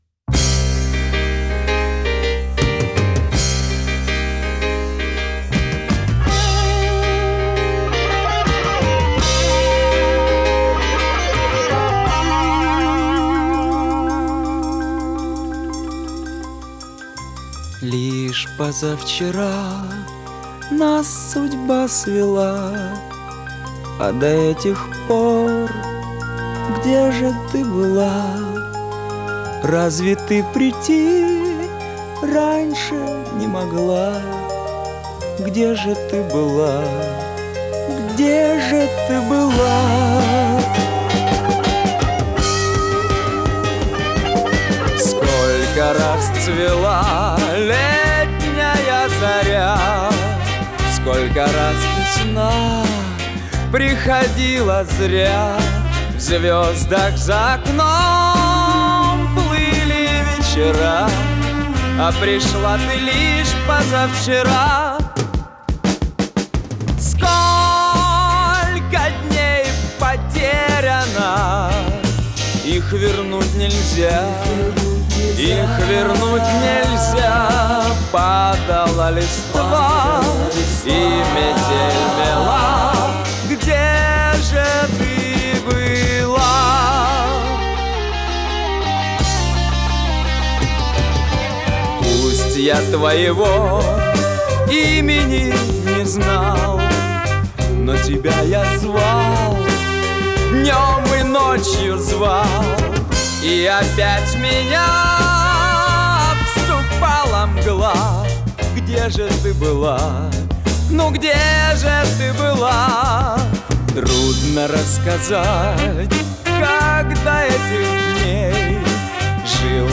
Тут качество получше будет